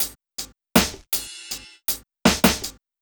80 toploop.wav